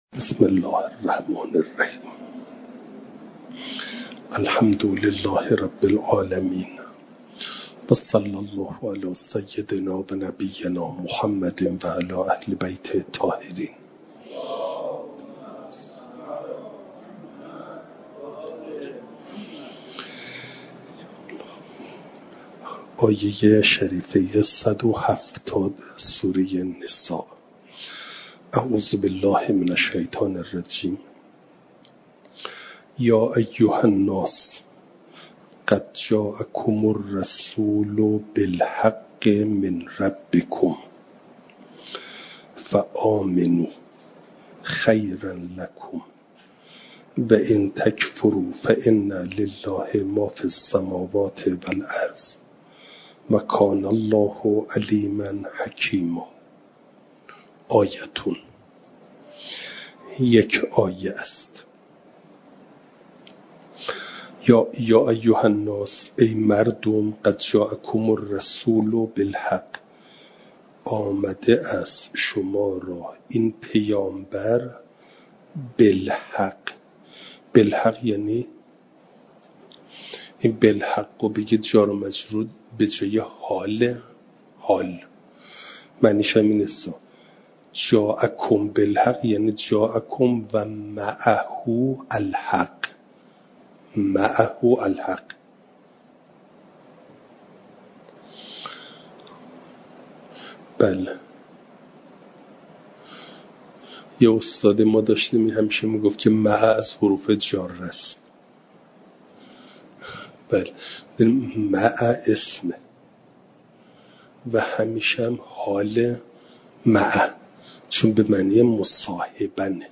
درس تفسیر